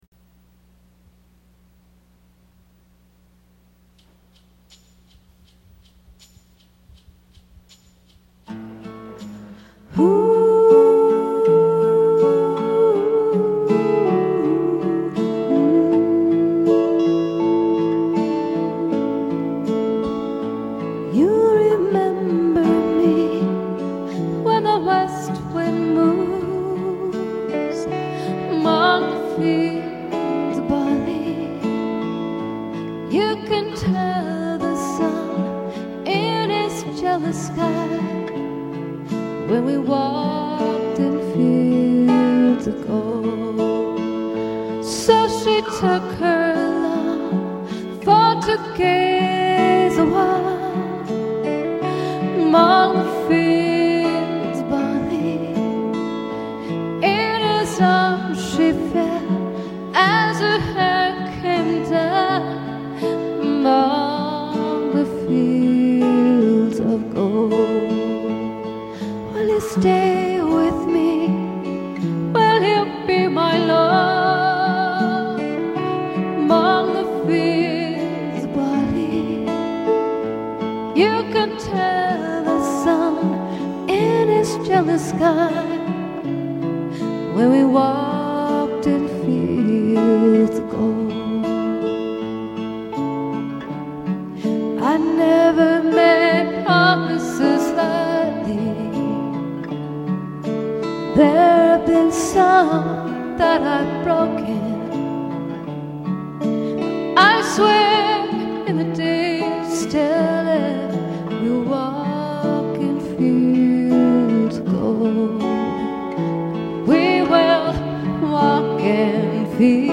musical duo featuring vocalist/keyboardist